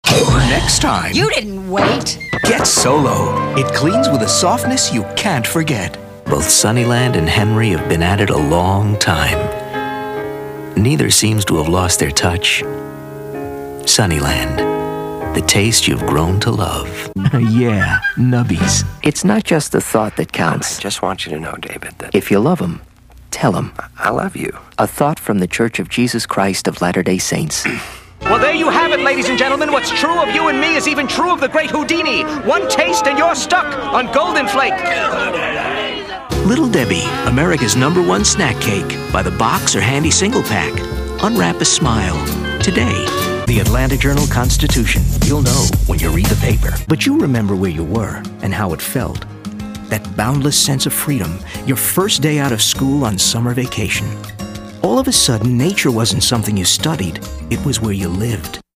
smooth, casual, and velvety. Not weak by any means, but natural and unaffected.
commercial.mp3